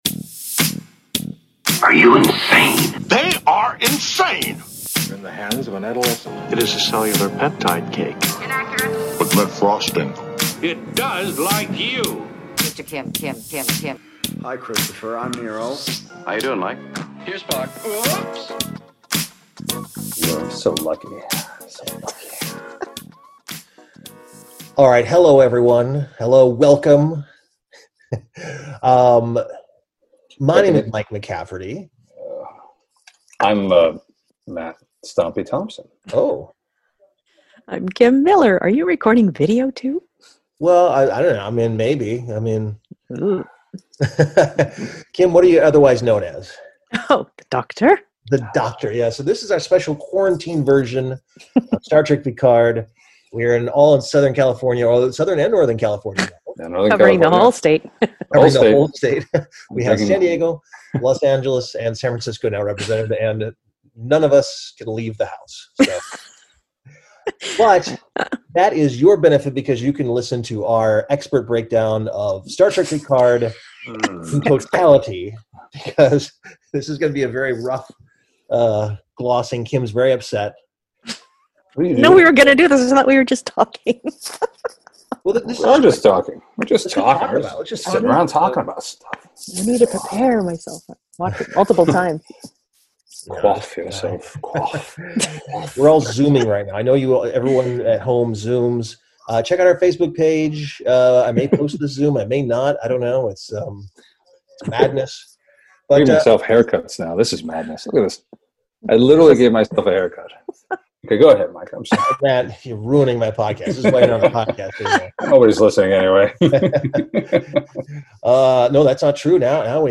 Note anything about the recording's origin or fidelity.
hold a Zoom session to go over their thoughts of this season of Picard.